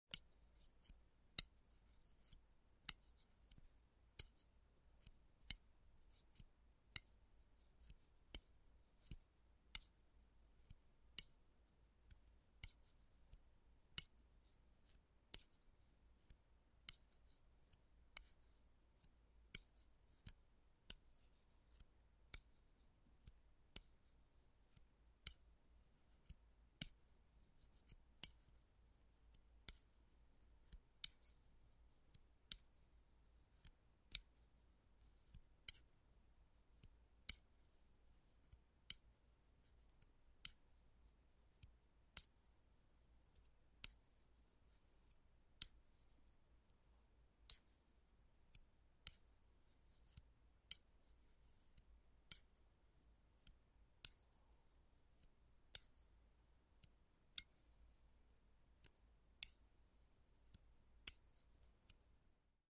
На этой странице собраны звуки вязания: мягкое постукивание спиц, шелест пряжи, ритмичные движения рук.
Продолжительный звук вязания спицами